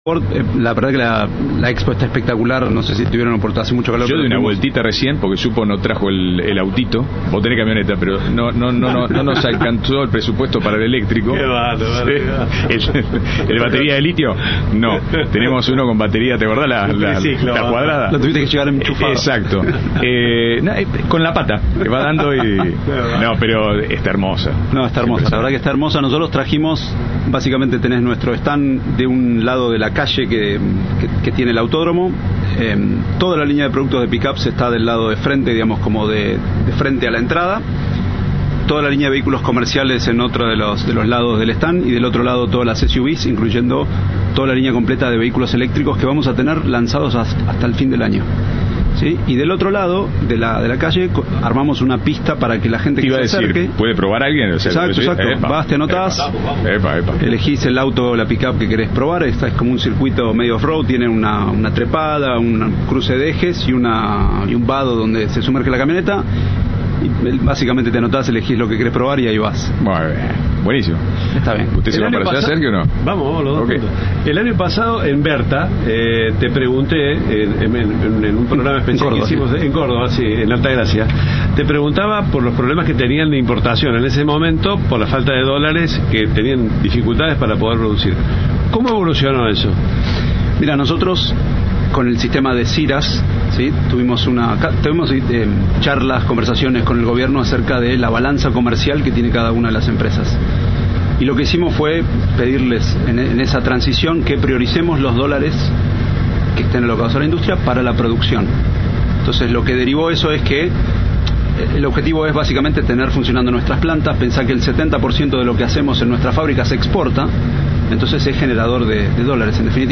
Entrevista de Rodolfo Barili